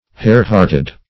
Search Result for " hare-hearted" : The Collaborative International Dictionary of English v.0.48: Hare-hearted \Hare"-heart`ed\ (-h[aum]rt`[e^]d), a. Timorous; timid; easily frightened.
hare-hearted.mp3